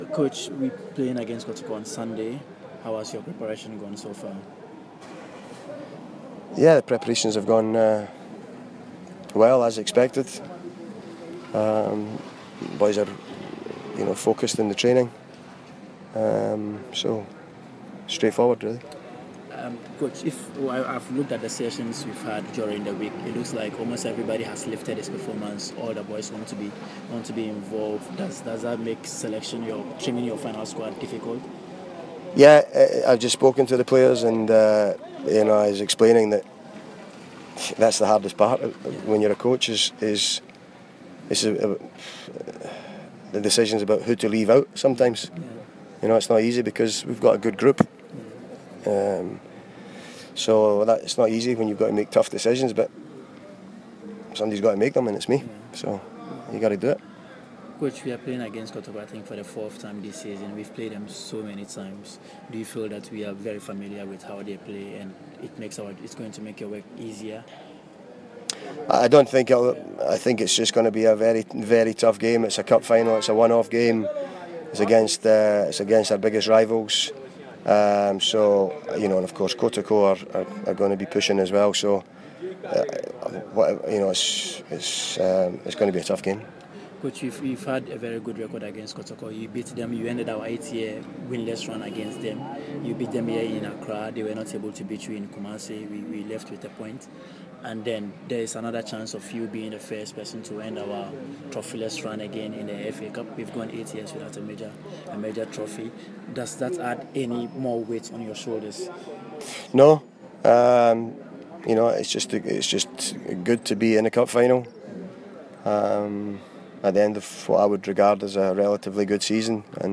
FA CUP FINAL PRE-MATCH INTERVIEW